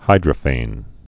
(hīdrə-fān)